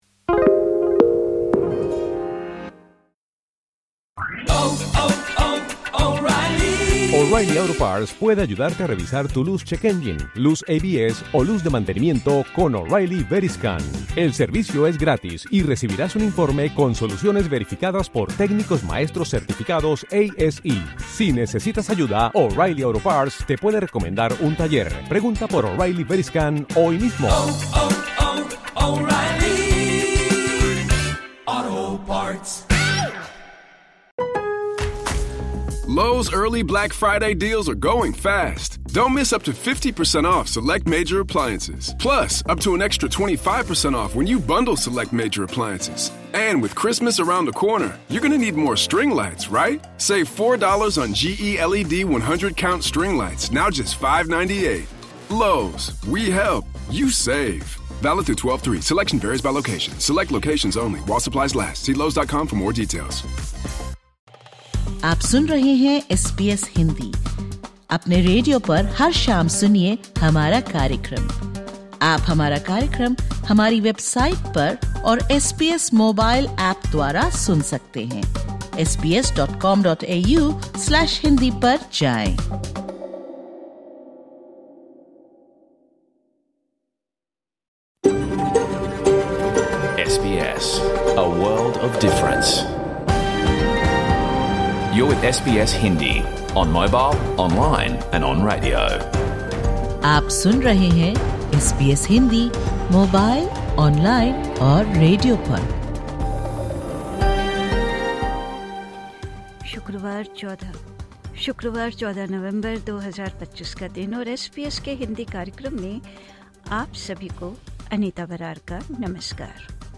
Catch the full episode of SBS Hindi radio program